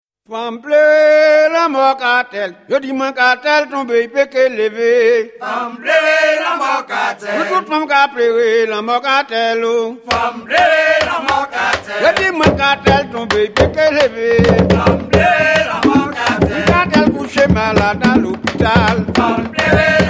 La musique bèlè possède une discographie très fournie.